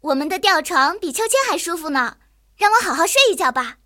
野牛中破修理语音.OGG